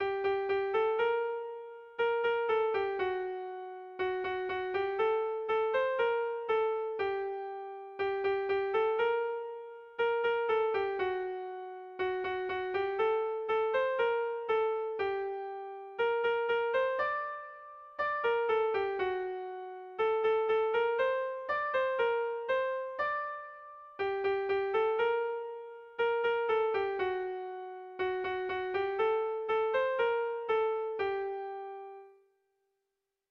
Zortzi puntuko berdina, 10 silabaz
AABA